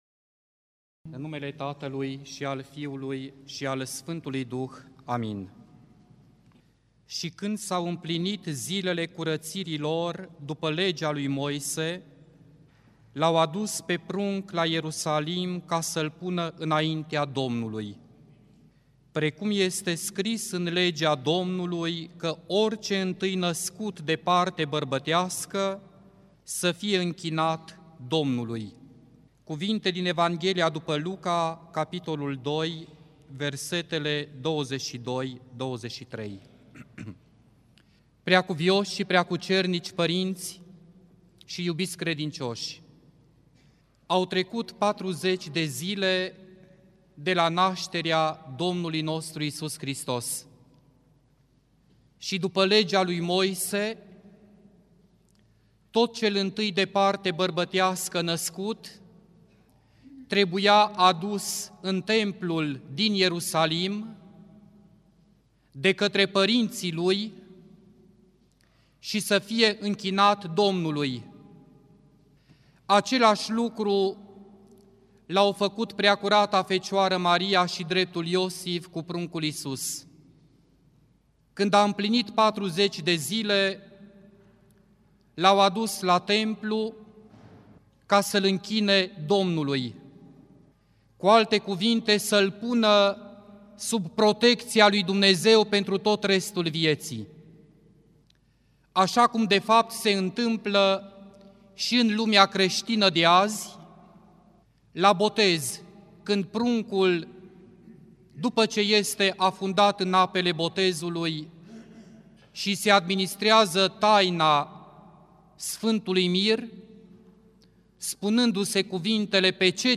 Predică la sărbătoarea Întâmpinării Domnului
rostit la sărbătoarea Întâmpinării Domnului